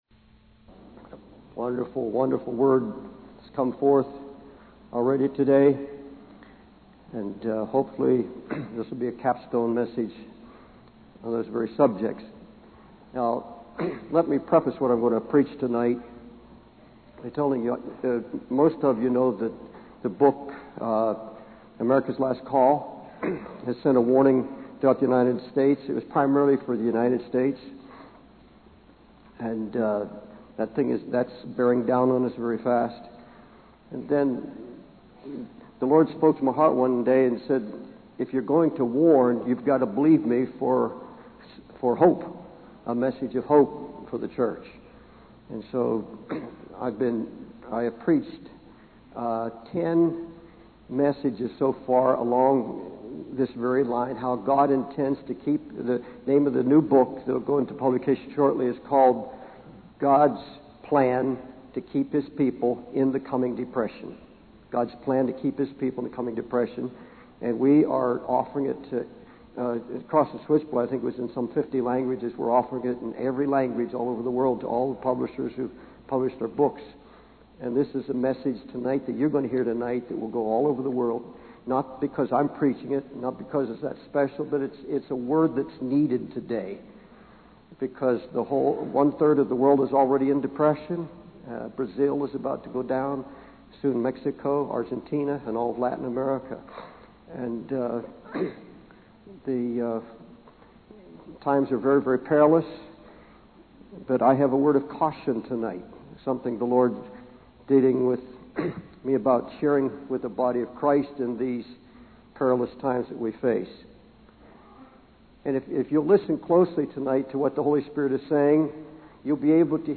In this sermon, the preacher begins by referencing Isaiah 24 and the prophecy of a watchman.